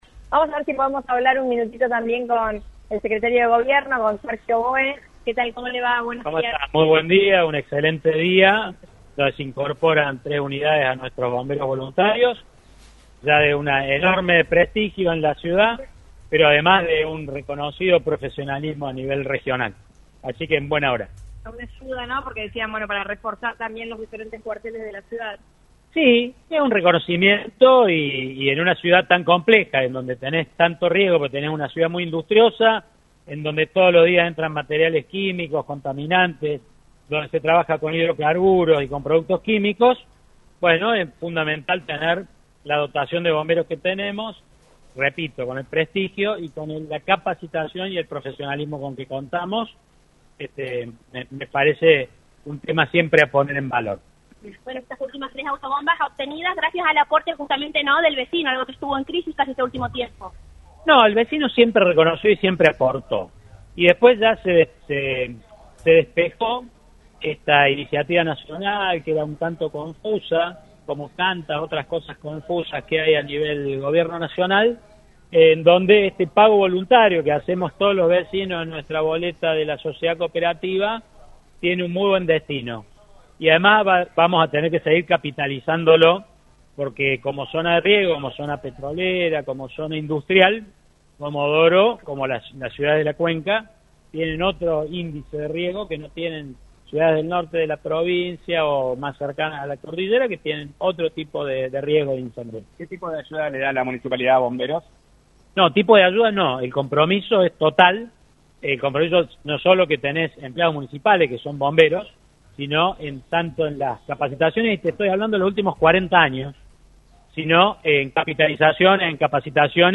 Sergio Bohe, secretario de Gobierno de Comodoro Rivadavia, pasó por los micrófonos de LaCienPuntoUno donde realizó un balance de este primer año de gestión de Othar Macharashvili al frente del municipio.